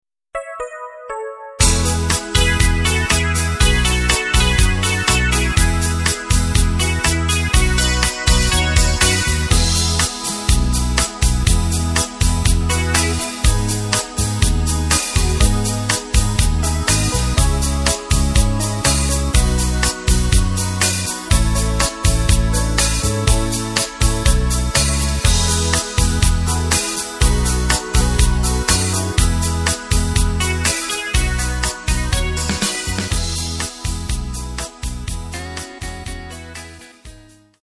Takt: 4/4 Tempo: 120.00 Tonart: E
Kroatischer Schlager